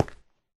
Sound / Minecraft / step / stone4